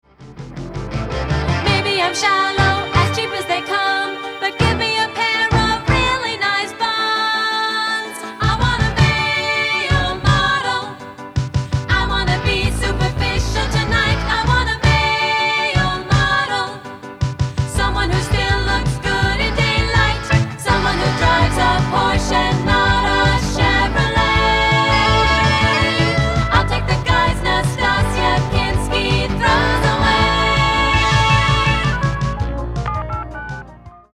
- our only STUDIO recording!